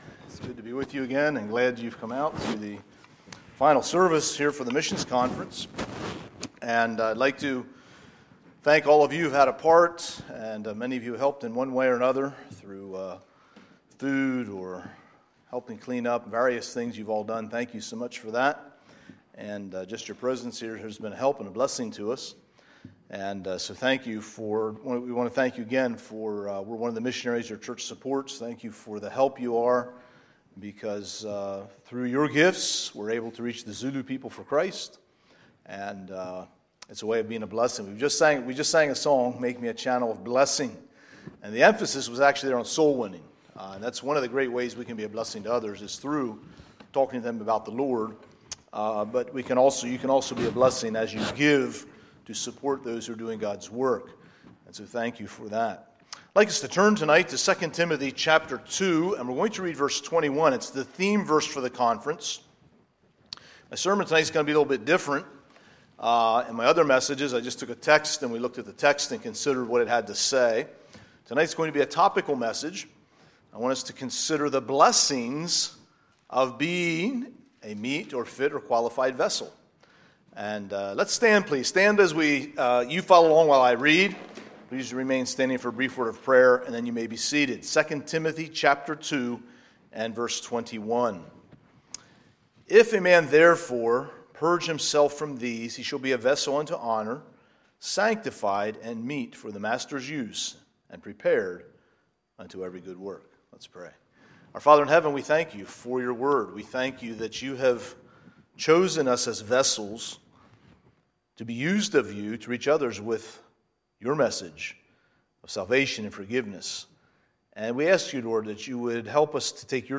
Wednesday, September 25, 2019 – Missions Conference Session 6